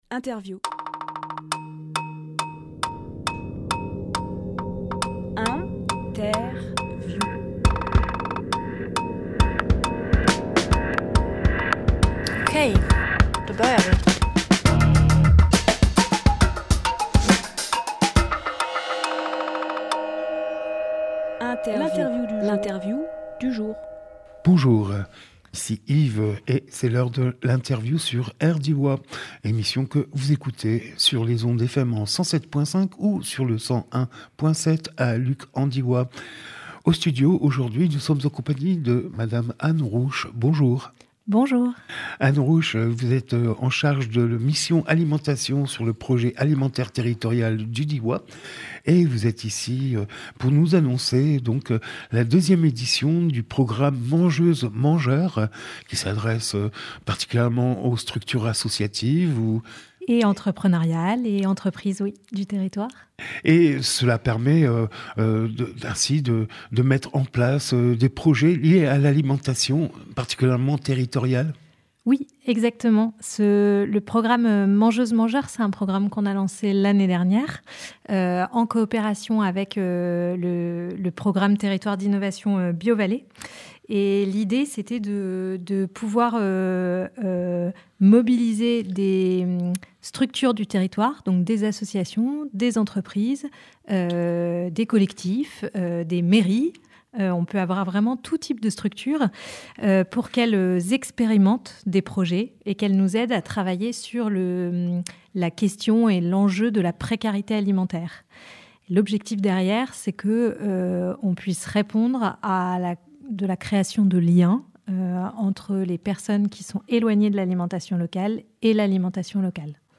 Emission - Interview Appel à Projets par la seconde édition de ” Mangeuses !
14.12.23 Lieu : Studio RDWA Durée